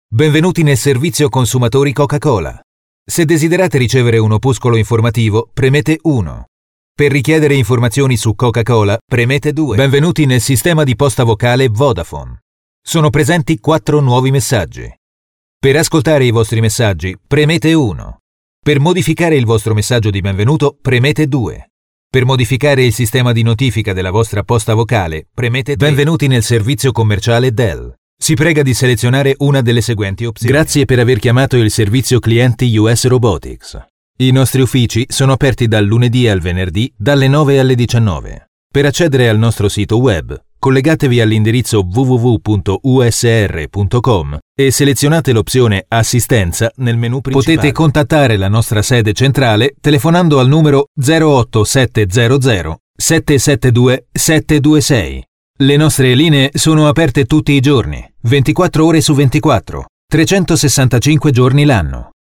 The versatility of his voice enables him to provide many varying styles, accents and tones from mild sophisticated and corporate to a rich and sexy.
Sprecher italienisch.
Sprechprobe: Industrie (Muttersprache):
italian voice over artist.